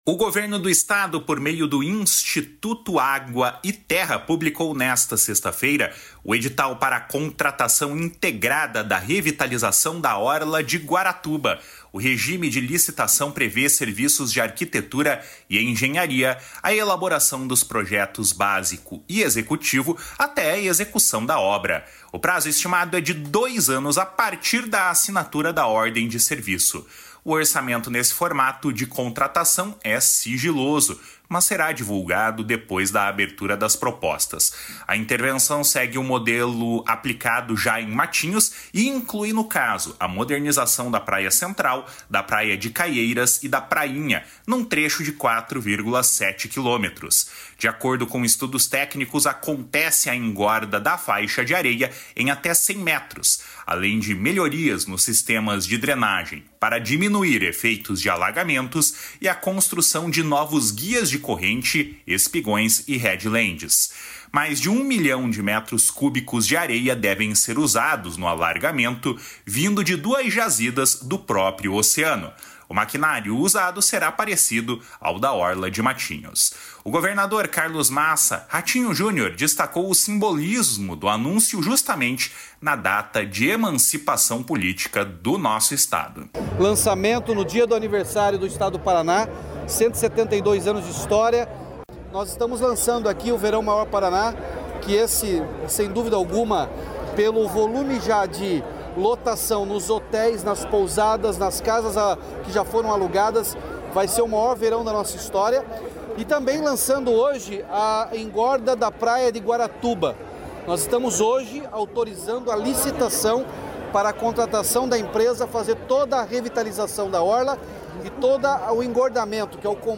O governador Carlos Massa Ratinho Junior destacou o simbolismo do anúncio justamente na data de emancipação política do Estado. // SONORA RATINHO JUNIOR //
O prefeito de Guaratuba, Mauricio Lense, afirmou que a cidade passa, basicamente, por uma revitalização total por causa de obras recentes promovidas pelo Estado. // SONORA MAURICIO LENSE //